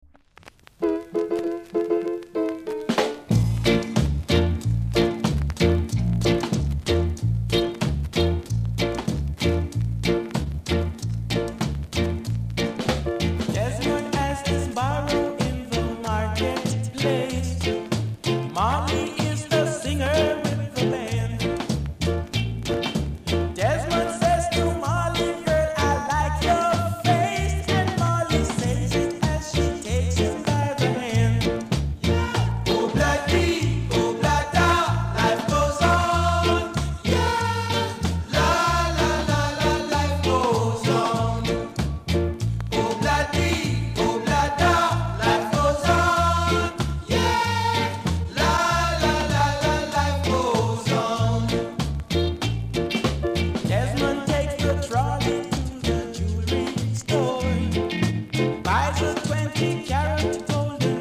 ※チリ、パチノイズが単発で少しあります。